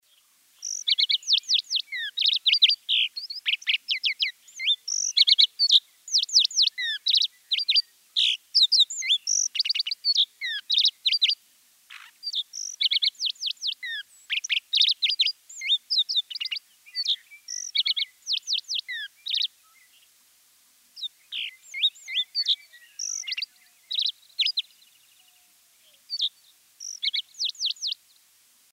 Звуки птиц
На этой странице собраны разнообразные звуки птиц: от щебетания воробьев до трелей соловья.